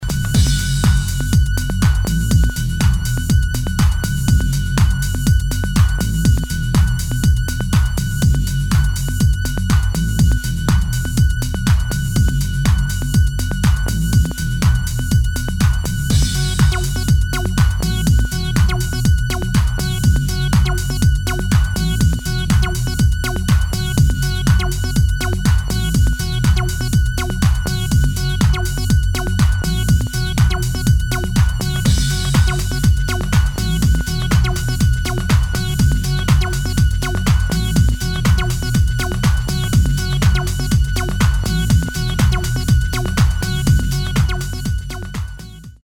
[ TECHNO ]